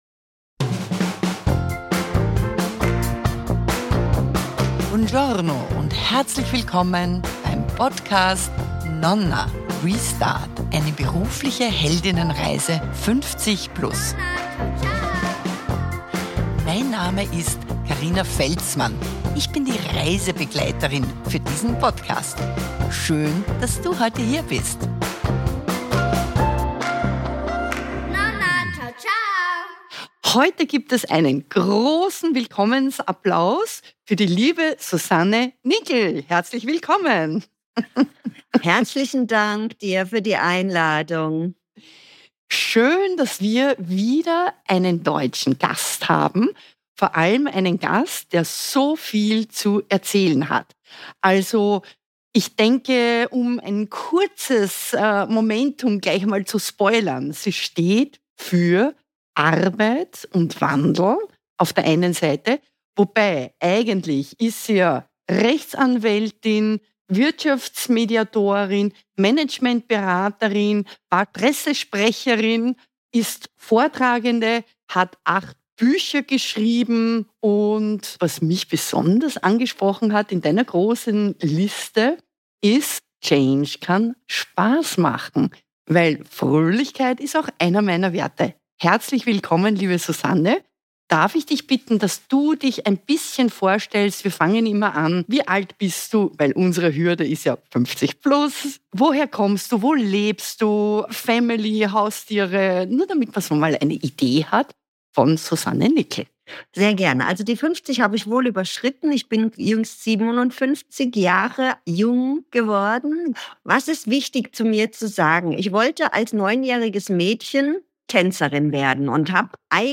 Podcasterin und Coachin